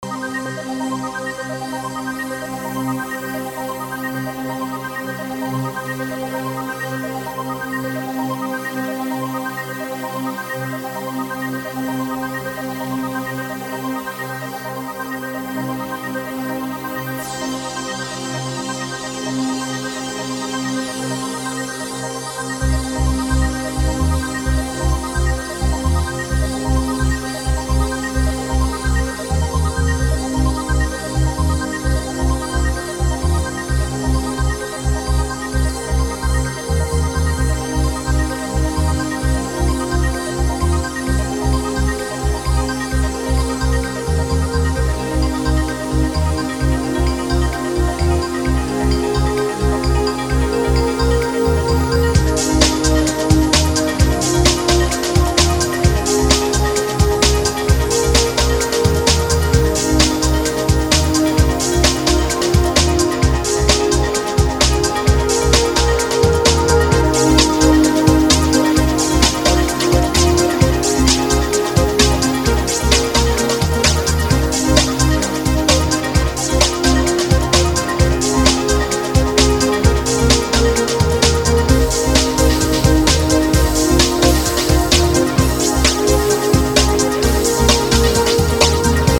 House evolving with a special signature in this 5 tracker.